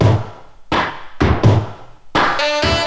• 84 Bpm 00s Disco Drum Loop A Key.wav
Free breakbeat - kick tuned to the A note. Loudest frequency: 1147Hz
84-bpm-00s-disco-drum-loop-a-key-W1v.wav